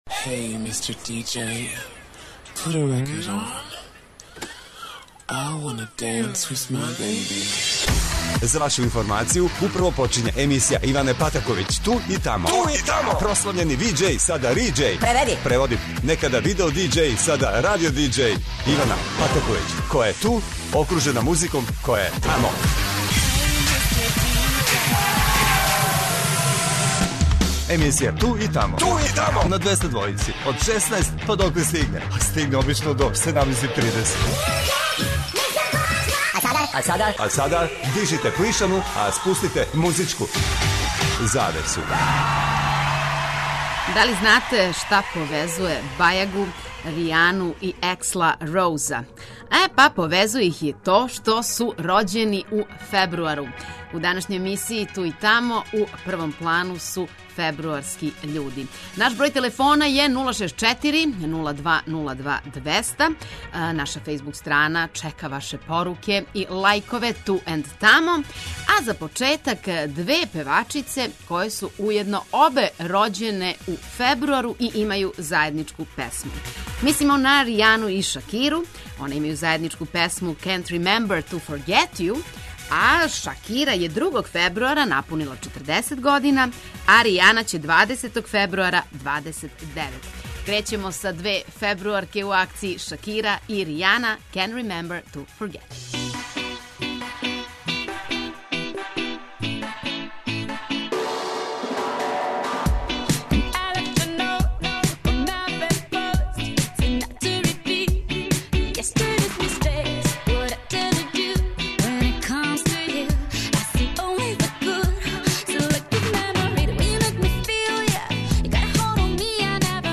Слушаоци као и увек могу да се укључе у тему и дају своје предлоге...
Очекују вас велики хитови, страни и домаћи, стари и нови, супер сарадње, песме из филмова, дуети и још много тога.